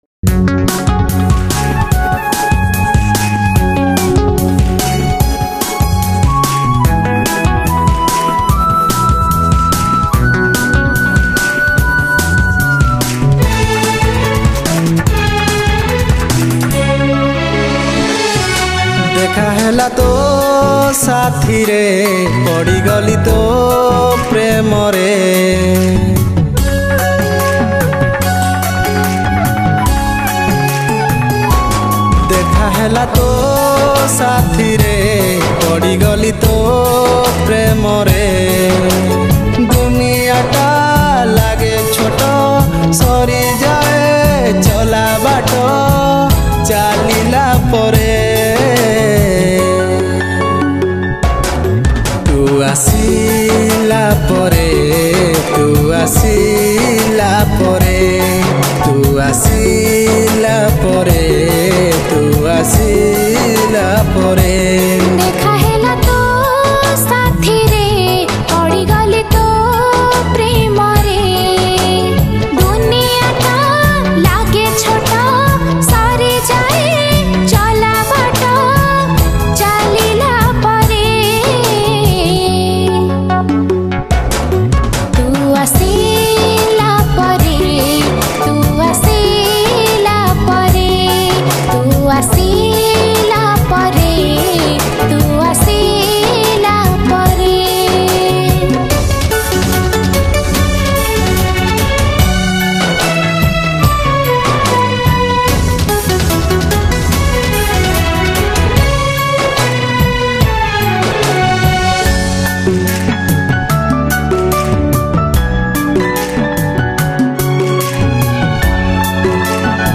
Odia New Romantic Song